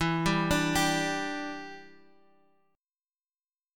G5/E chord